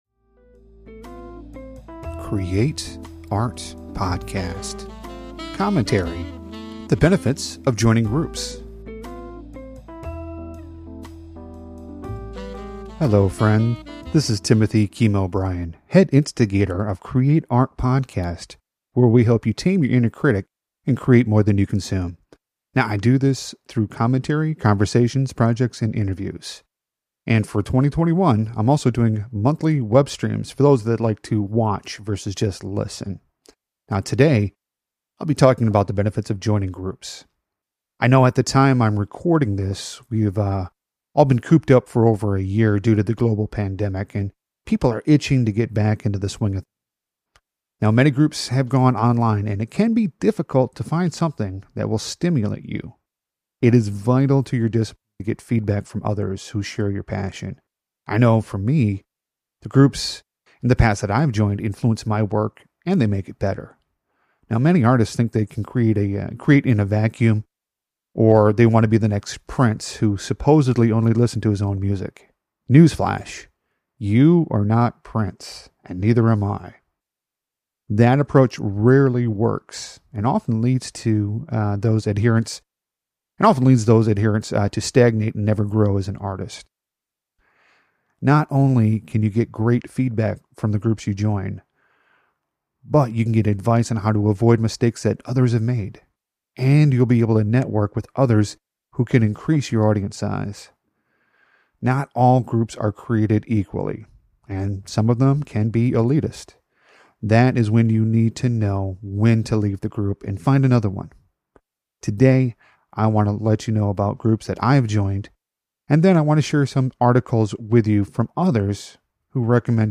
Commentary The Benefits of Joining Groups